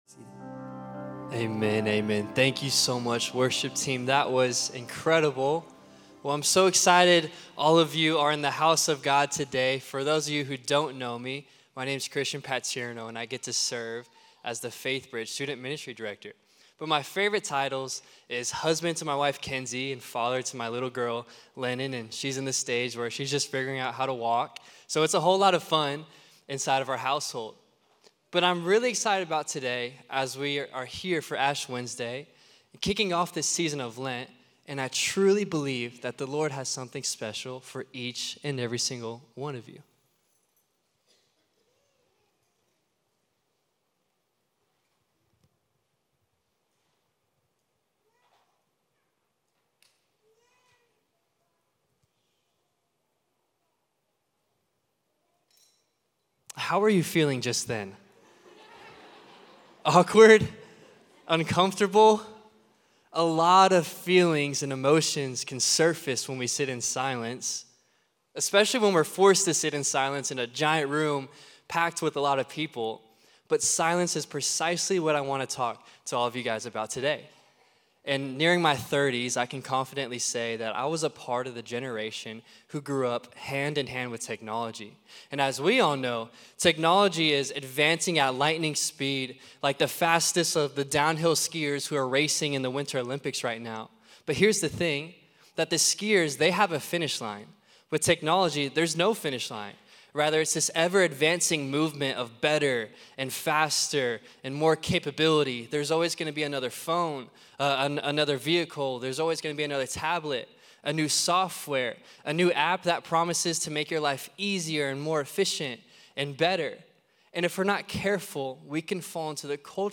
Faithbridge Sermons Ash Wednesday 2026 Feb 24 2026 | 00:16:42 1x 00:00 / 00:16:42 Subscribe Share Apple Podcasts Spotify Overcast RSS Feed Share Link Embed